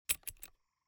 钥匙.ogg